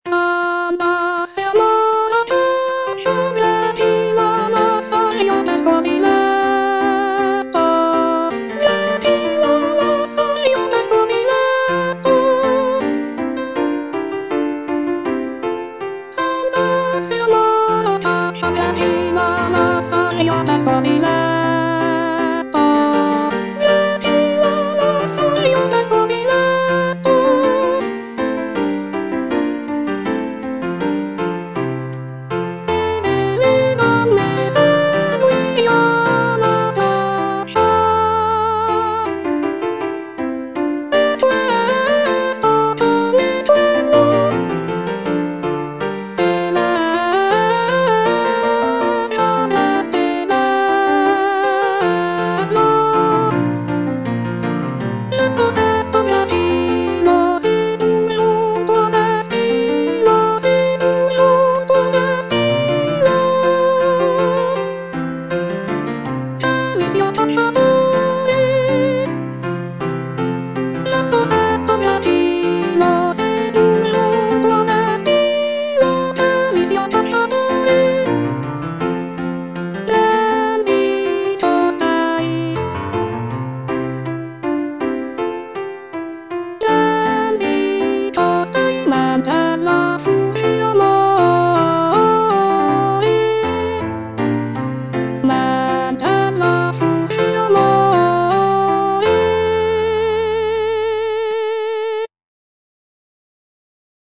C. MONTEVERDI - S'ANDASSE AMOR A CACCIA Soprano I (Voice with metronome, piano) Ads stop: